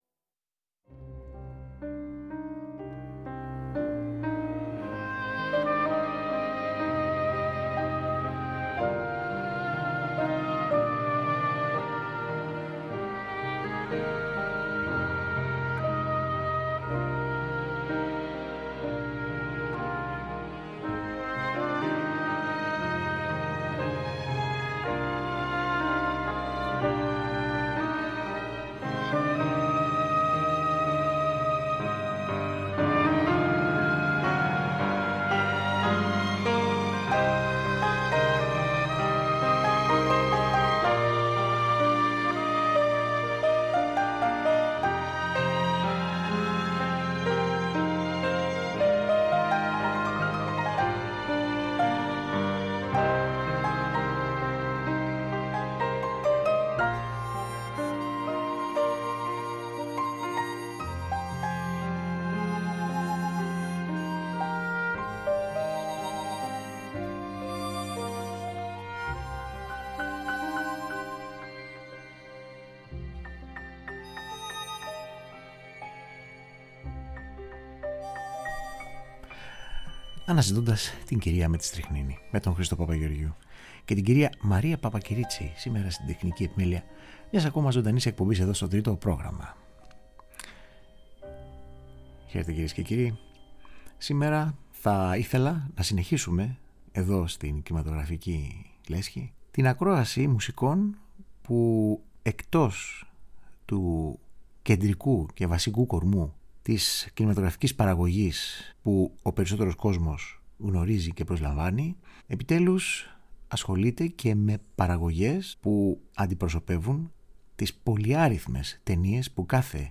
Κορυφαία Μουσικά Θέματα από τον Ευρωπαϊκό και Κινέζικο Κινηματογράφο.